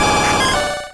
Cri de Poissoroy dans Pokémon Rouge et Bleu.